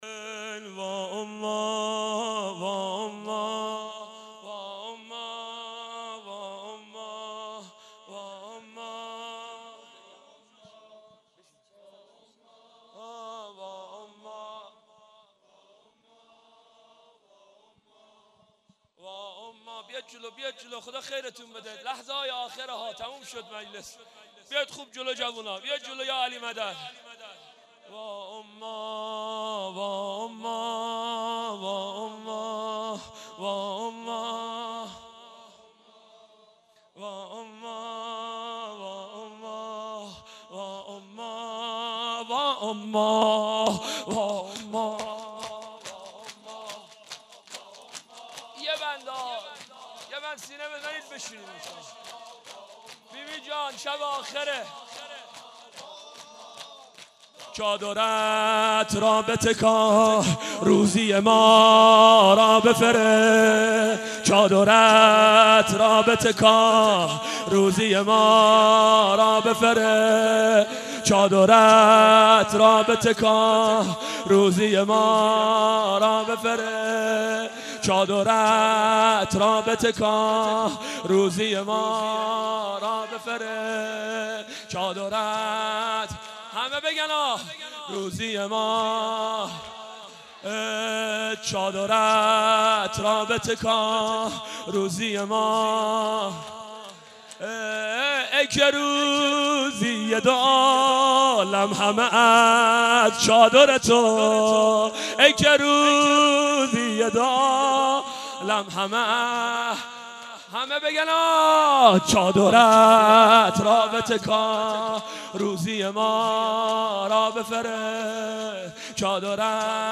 مجمع دلسوختگان بقیع- شب پنجم- شور